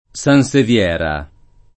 sansevieria [ S an S ev L$ r L a ]